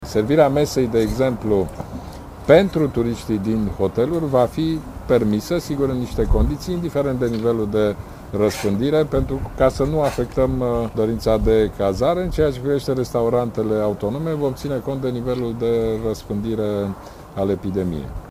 Ludovic Orban, aflat în vizită în județul Brașov: